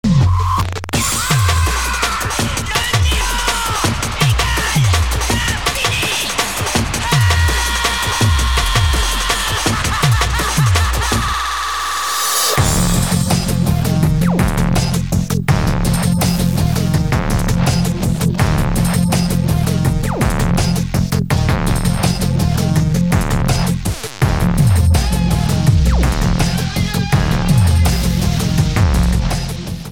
bas, gitaar, elektronica, mengtafel en vocoder